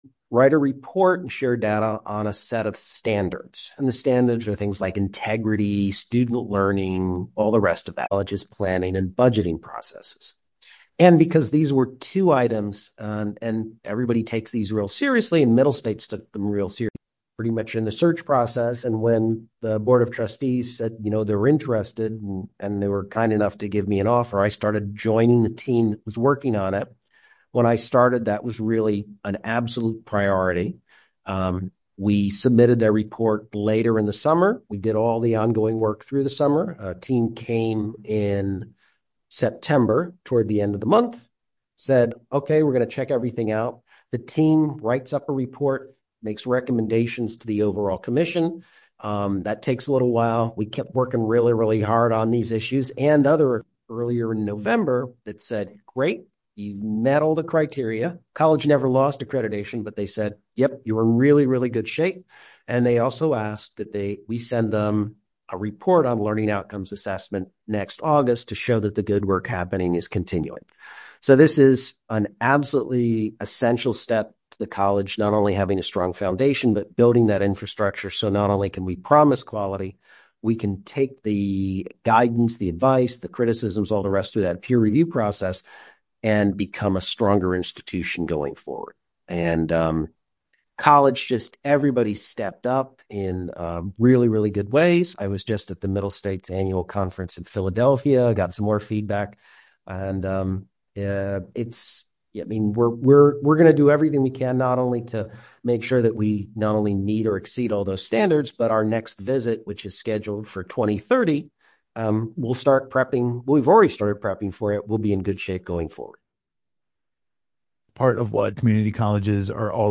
Live, local conversations focused on arts, history, and current news.